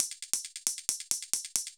Index of /musicradar/ultimate-hihat-samples/135bpm
UHH_ElectroHatA_135-02.wav